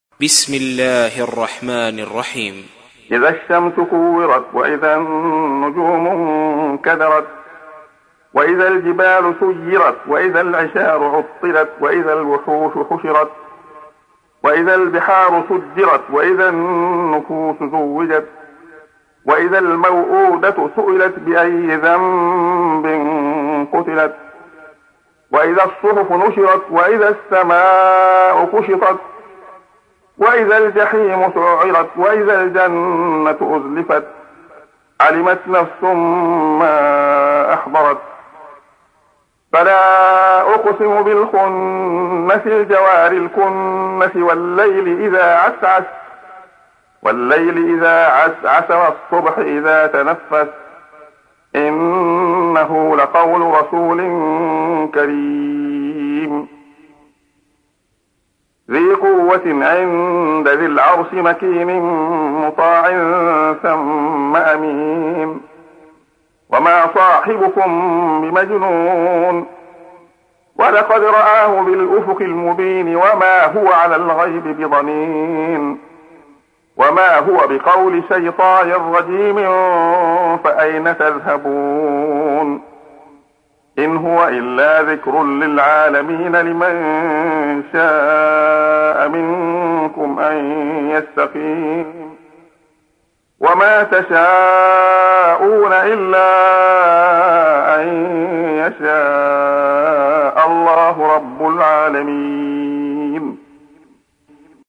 تحميل : 81. سورة التكوير / القارئ عبد الله خياط / القرآن الكريم / موقع يا حسين